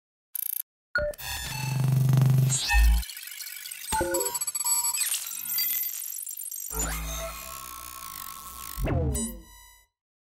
Здесь вы найдете как абстрактные шумы, так и более структурированные аудиоиллюзии.